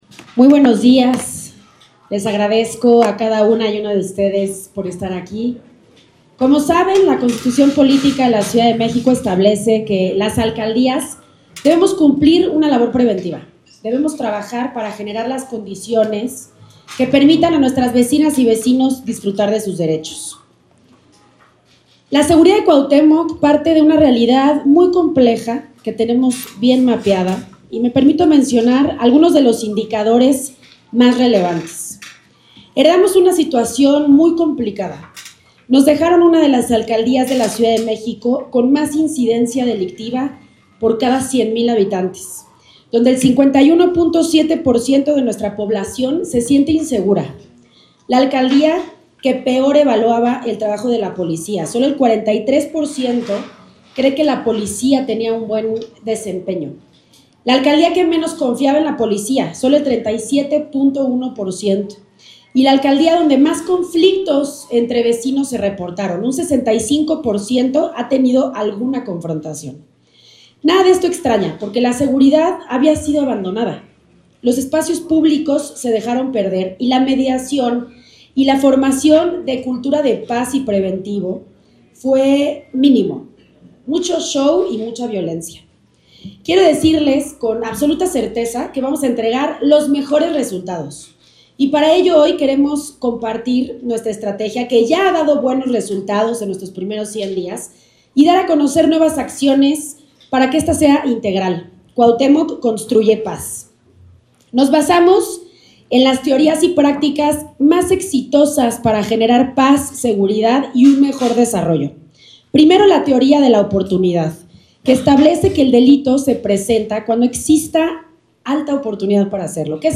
Conferencia-de-Prensa-Estrategia-de-Seguridad1.mp3